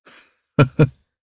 Kain-Vox-Laugh_jp.wav